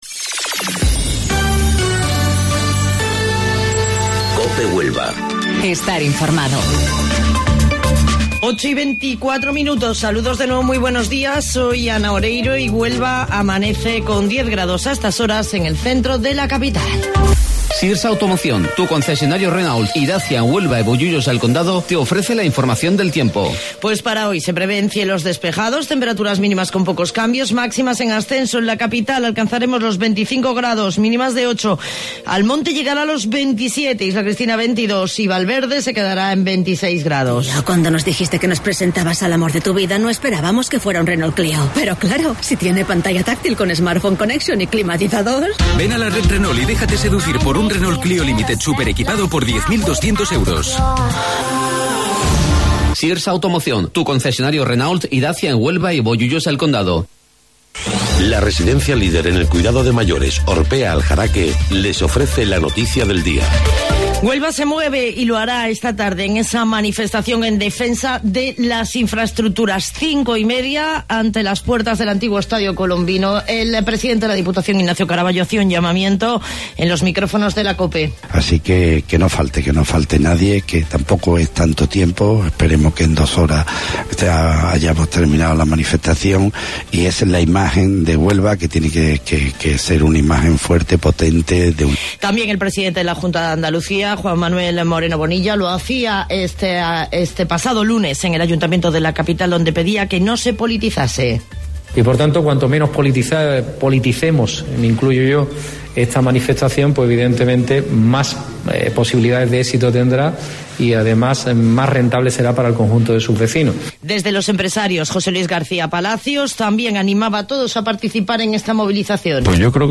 AUDIO: Informativo Local 08:25 del 14 de Marzo